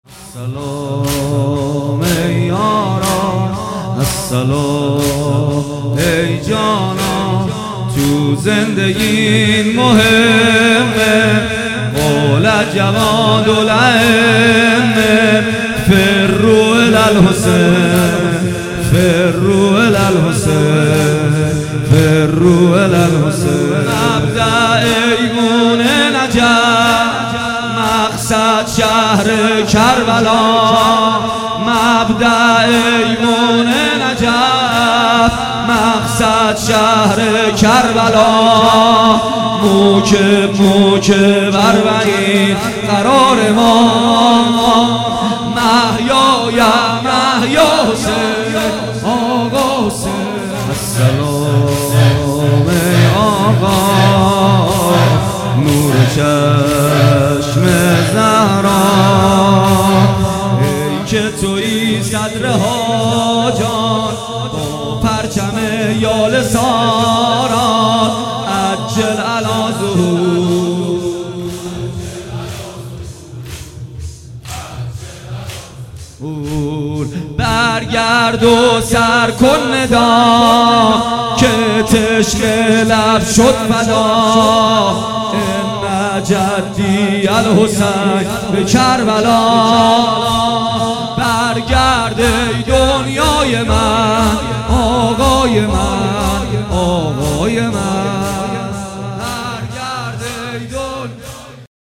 کربلایی محمدحسین پویانفر محرم الحرام 1441 هیئت ریحانه النبی تهران